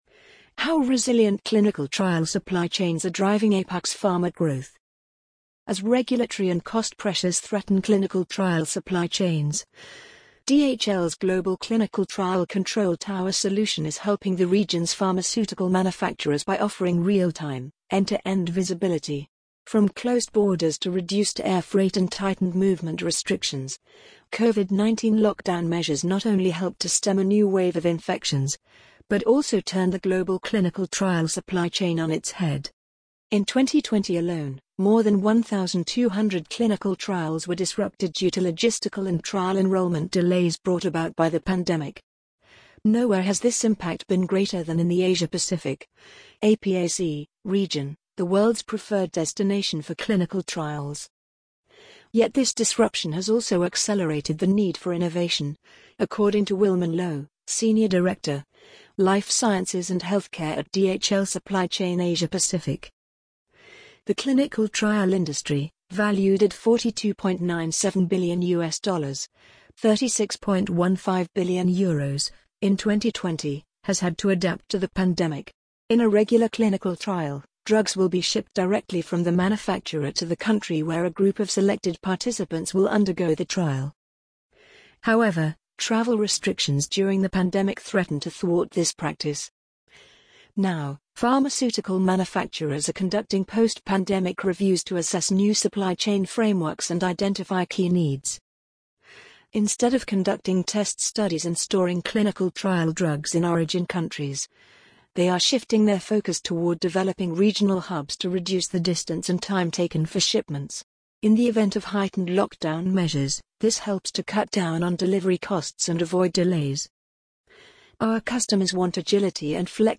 amazon_polly_15021.mp3